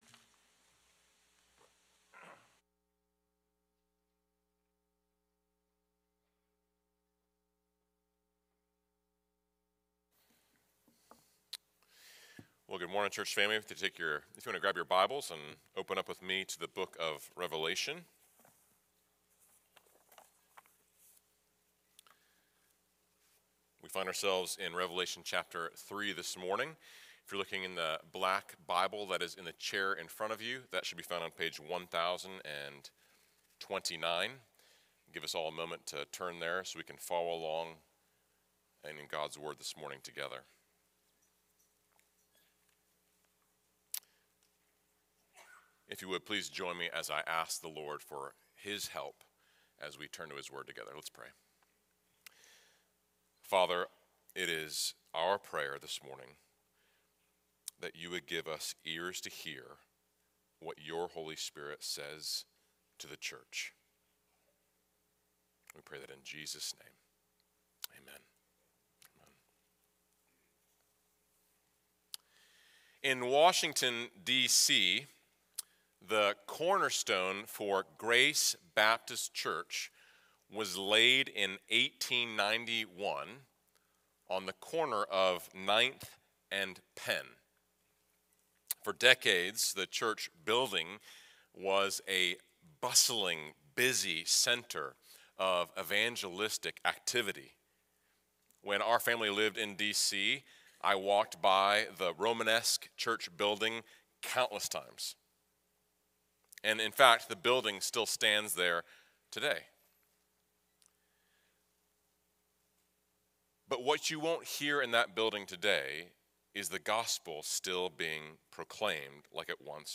How can a nominal, lifeless church or a nominal Christian be turned into a vibrant living church or a vibrant living Christian?. Sermon Outline 1.